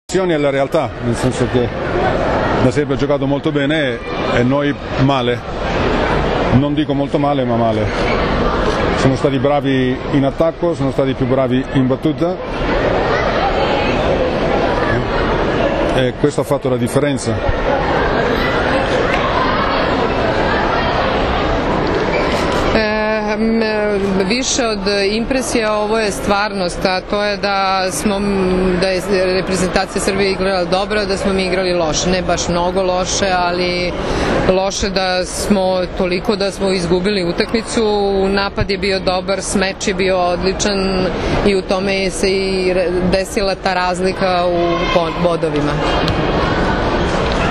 IZJAVA KAMILA PLAĆIJA